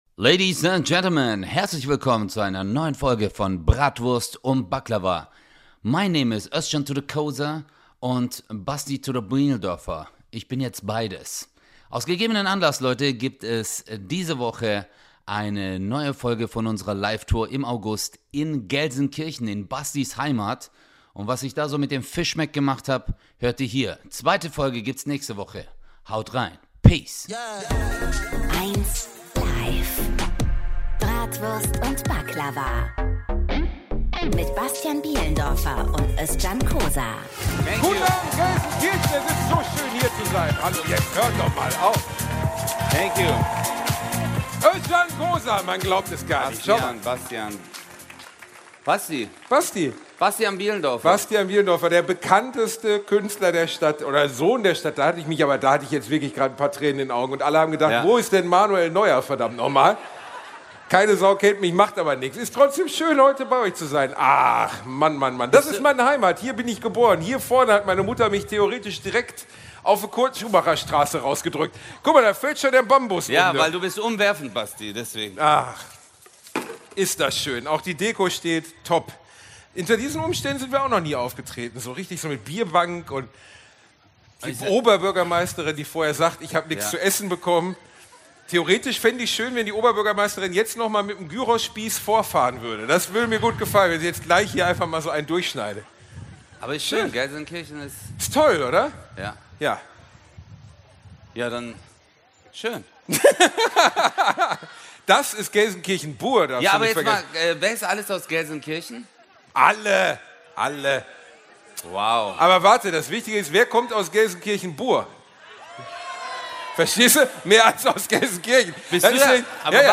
Heute gibt es den ersten Teil der Live-Show aus Gelsenkirchen Ende Oktober.